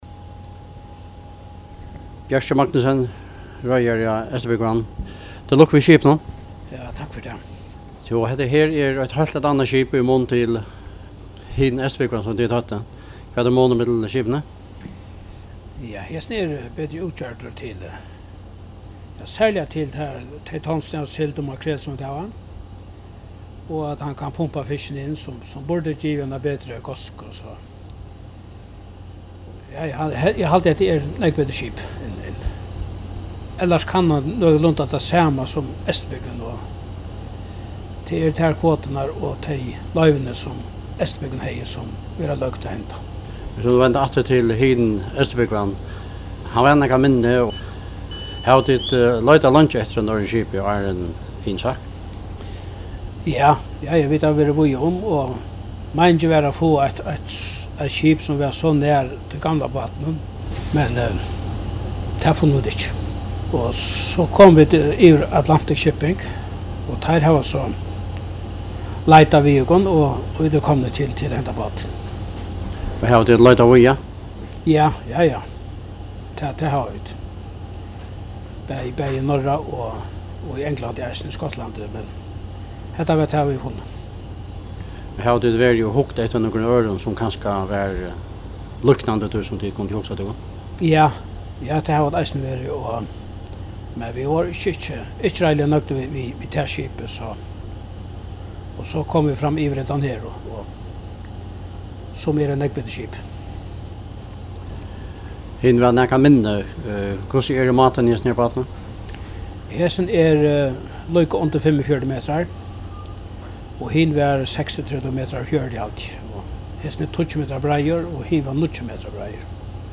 Samrøða